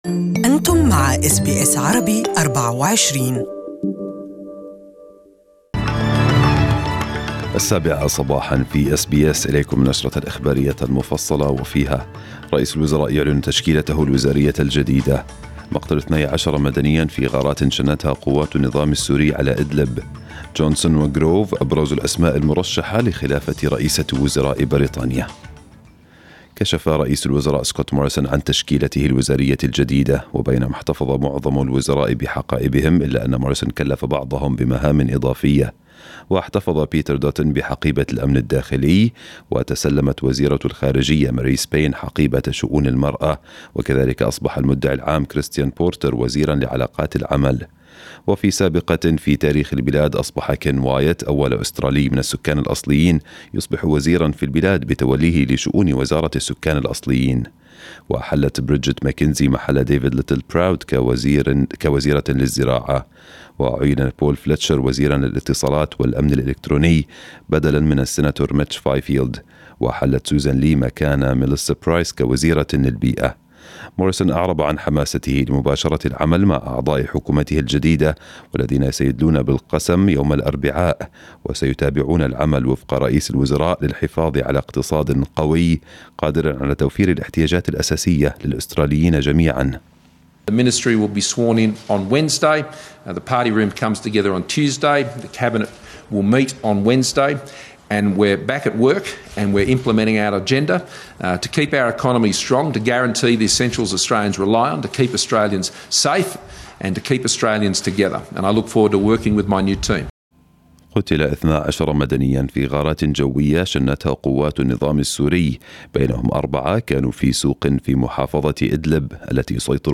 Morning news bulletin in Arabic 27/5/2019